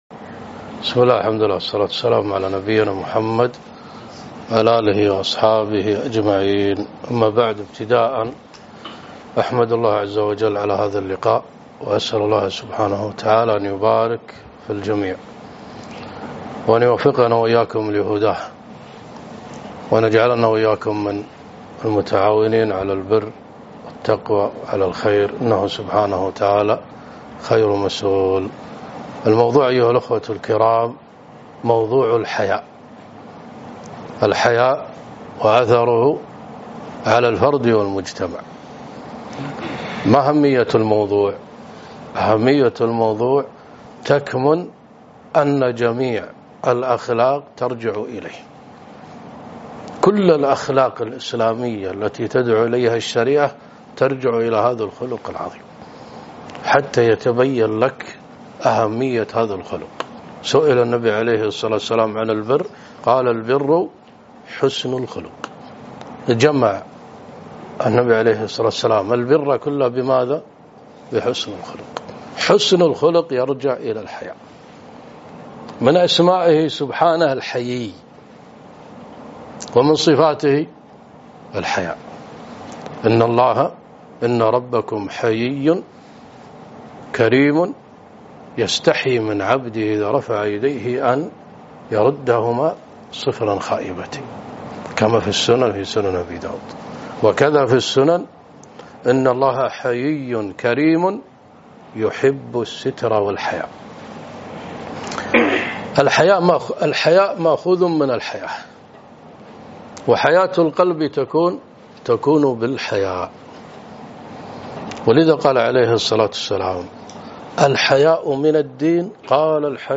محاضرة - الحياء وأثره في المجتمع